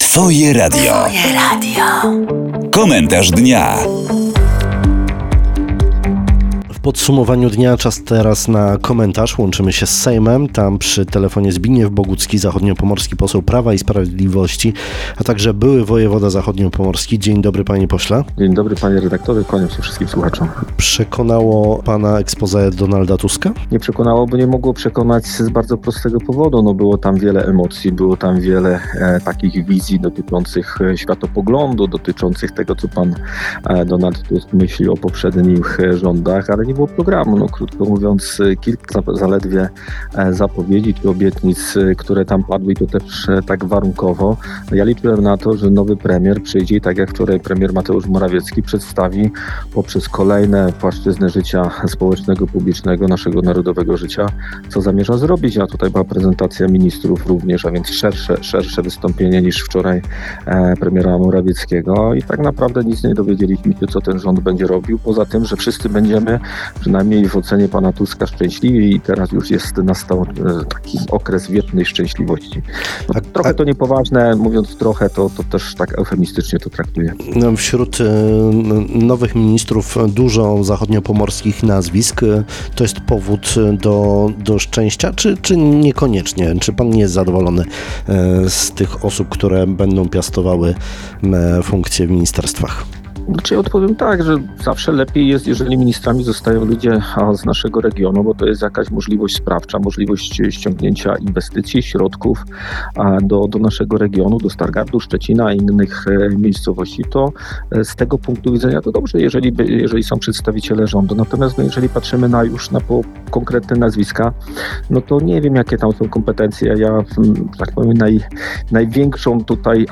W nowym rządzie ministrami z Pomorza Zachodniego są Sławomir Nitras, Katarzyna Kotula i Dariusz Wieczorek. O to był pytany w Komentarzu Dnia Twojego Radia zachodniopomorski poseł PiS Zbigniew Bogucki.